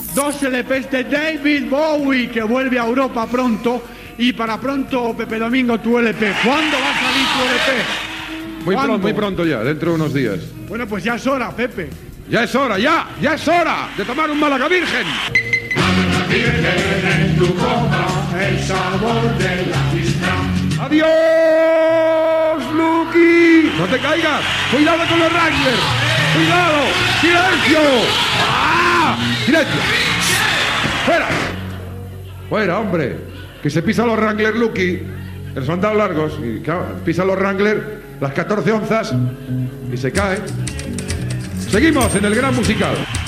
Fragment de "El gran musical" amb Pepe Domingo Castaño.
Musical